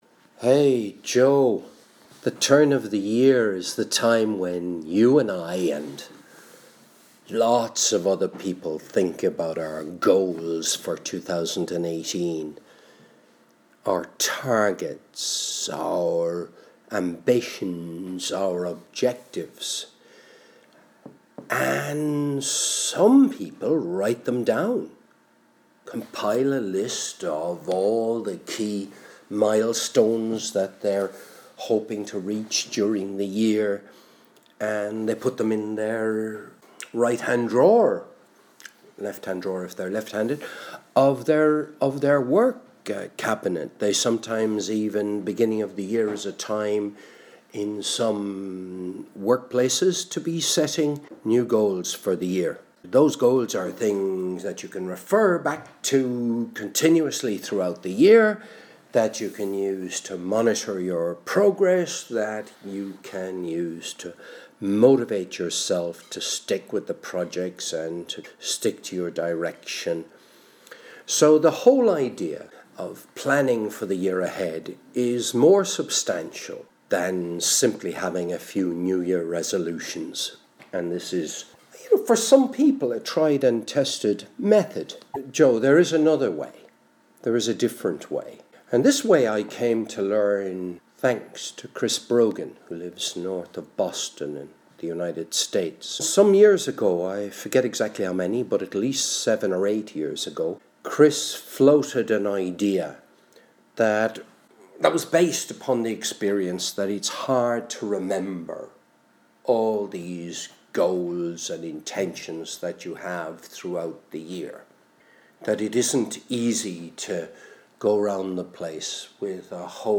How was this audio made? This was recorded while showering on the morning of Saturday 22nd of February 2025